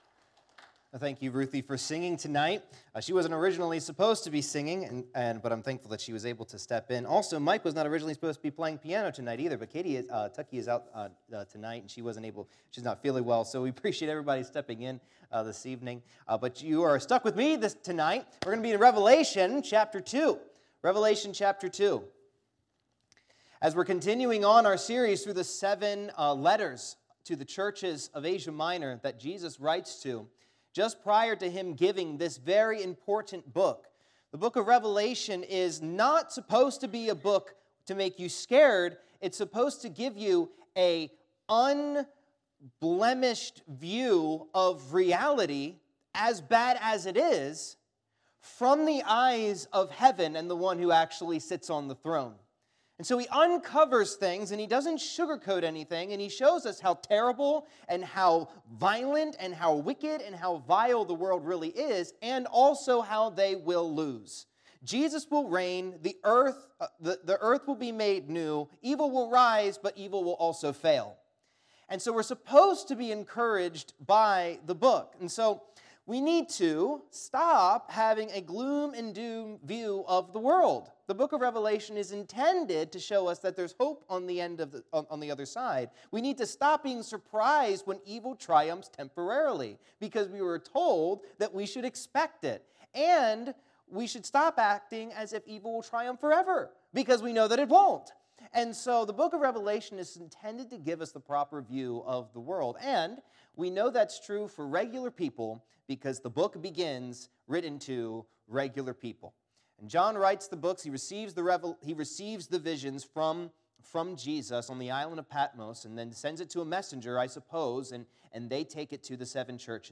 Lessons in Revelation Passage: Revelation 2:18-29 Service Type: Sunday Evening « Behold A Summation of the Ministry of Paul